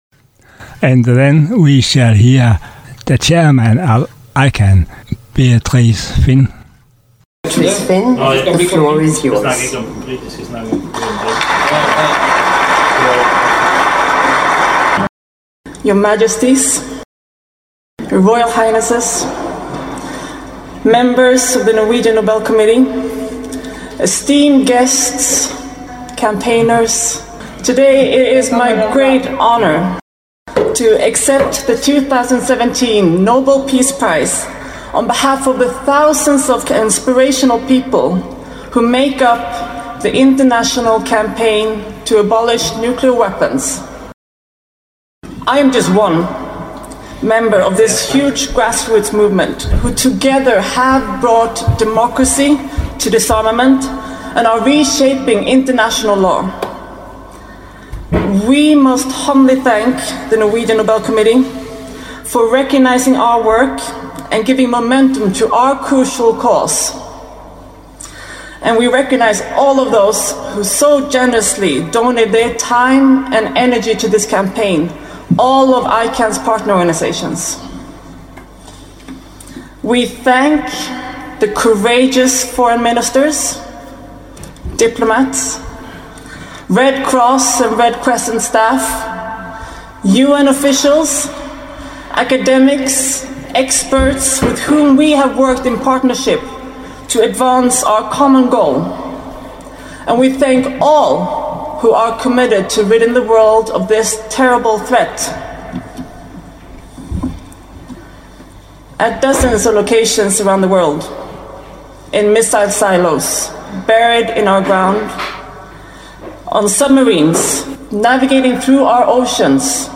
Her bringer Dialog den første del af interviews og optagelser i Oslo i anledning af at ICAN-kampagnen for afskaffelse af Atomvåben har modtaget Nobels fredspris d. 10. december 2017. Sammenslutningen ICAN får prisen for at skabe opmærksomhed på de katastrofale humanitære konsekvenser ved brugen af atomvåben.
(English speaking speeches)